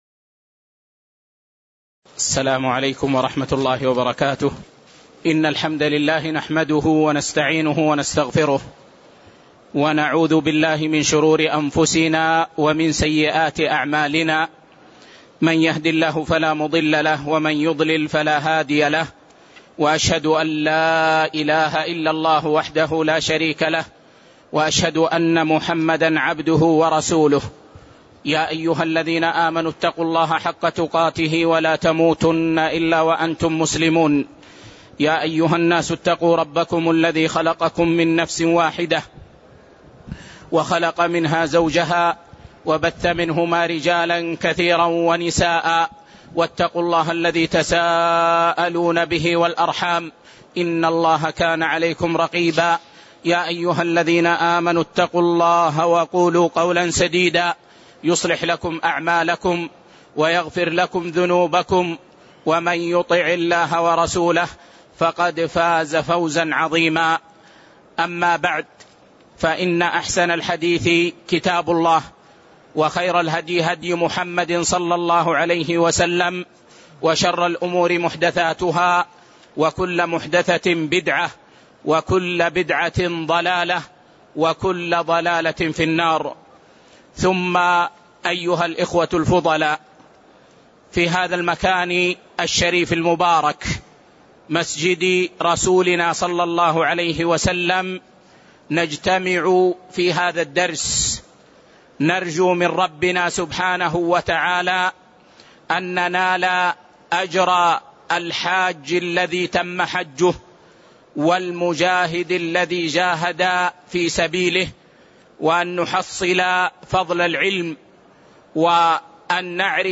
تاريخ النشر ٣ ذو الحجة ١٤٣٤ هـ المكان: المسجد النبوي الشيخ